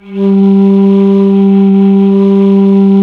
FLT ALTO F01.wav